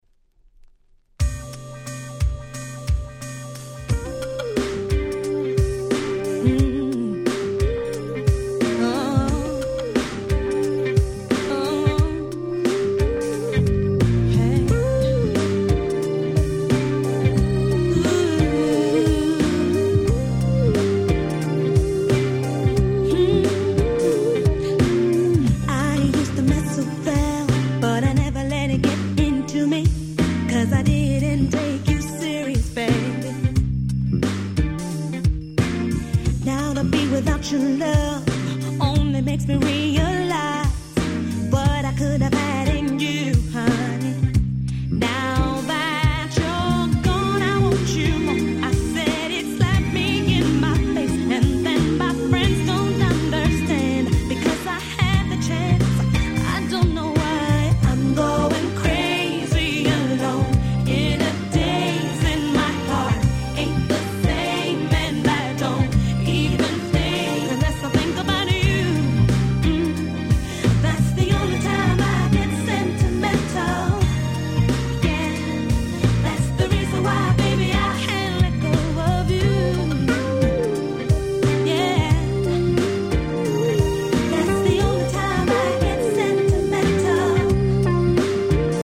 95' Super Hit R&B !!